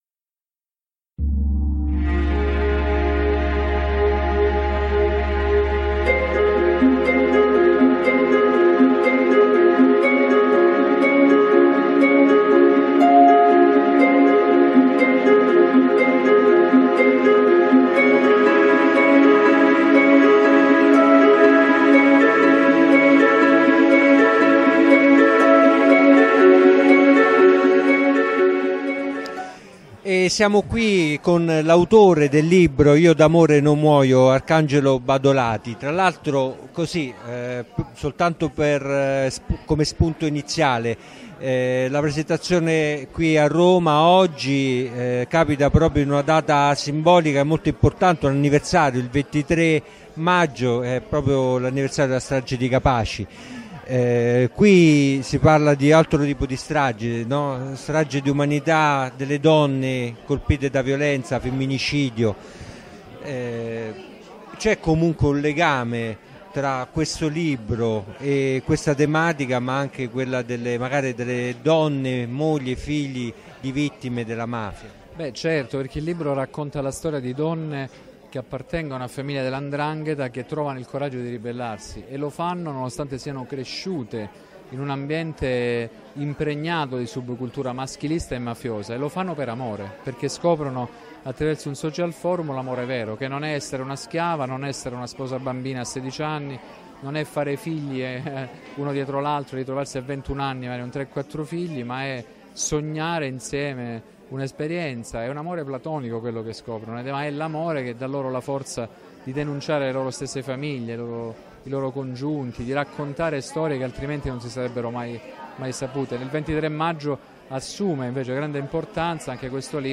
Roma, 23 maggio 2016.
Intervista